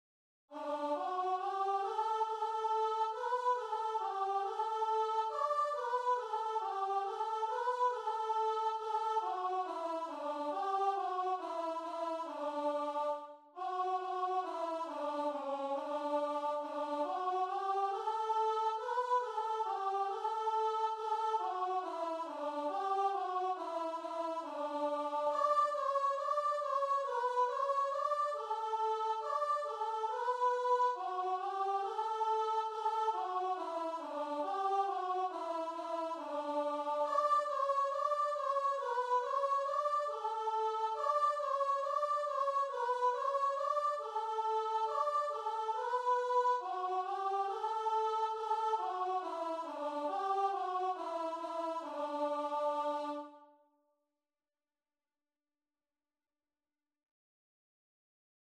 4/4 (View more 4/4 Music)
Db5-D6
D major (Sounding Pitch) (View more D major Music for Voice )
Christian (View more Christian Voice Music)